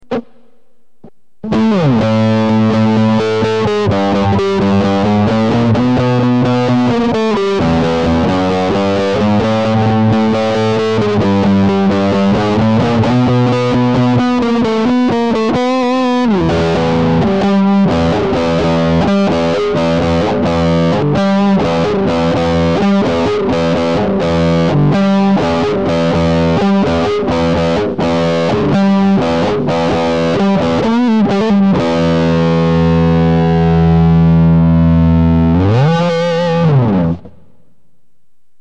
Mozda je malkice preglasno...